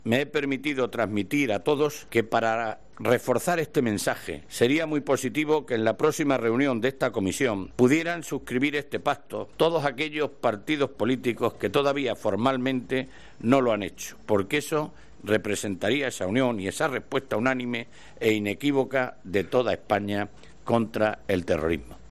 Zoido ha comparecido en rueda de prensa tras la reunión del pacto, al que han acudido por primera vez, como observadores, partidos nacionalistas que no lo firmaron, como ERC, PDeCAT y PNV, además de Podemos que ya ha asistido a otras reuniones de la comisión también como observador.